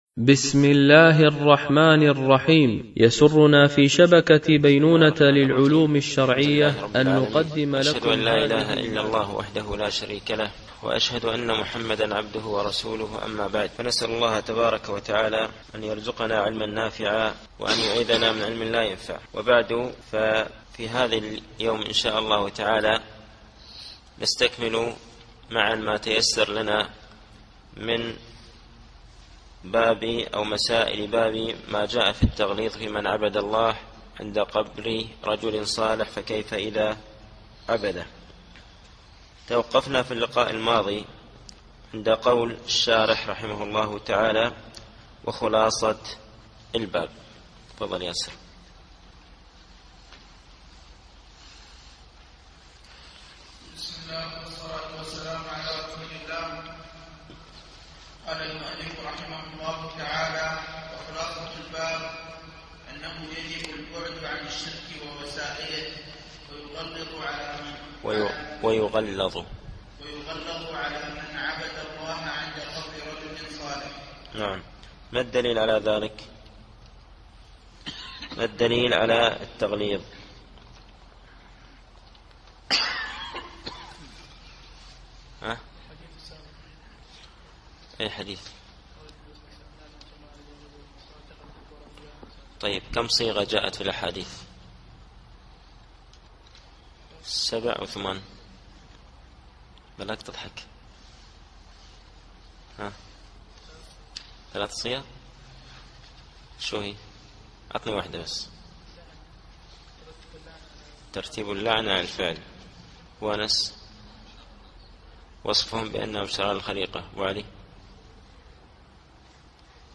التعليق على القول المفيد على كتاب التوحيد ـ الدرس الستون